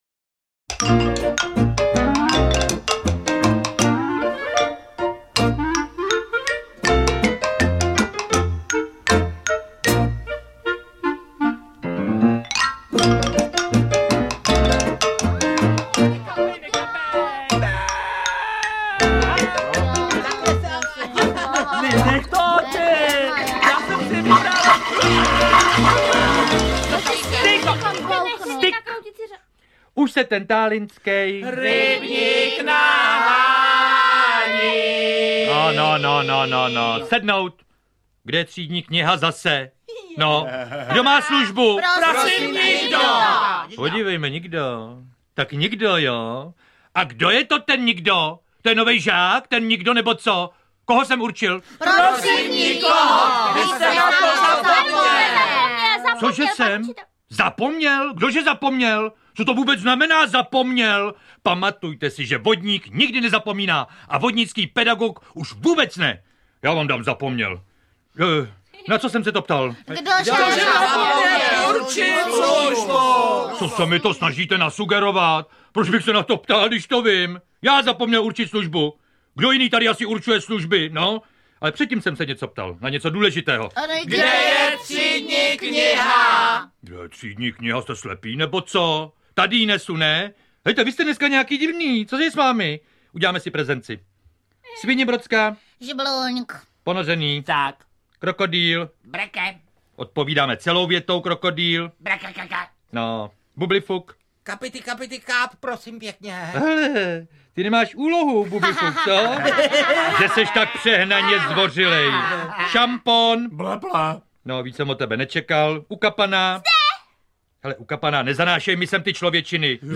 Vyberte Audiokniha 99 Kč Další informace
V podvodní škole se ale nasmějete, protože žáky představují dobří herci i komici. O přestávkách si navíc všichni rádi zazpívají vtipné písničky.